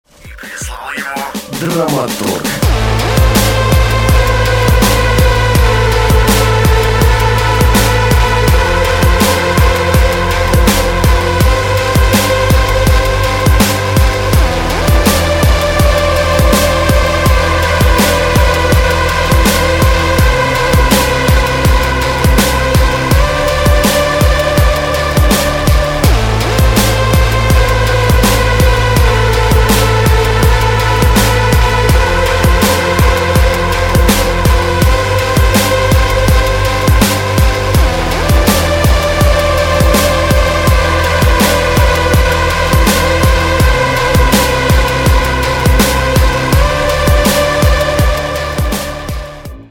• Качество: 160, Stereo
громкие
Electronic
Dubstep
мрачные
Необычный и мрачноватый трек.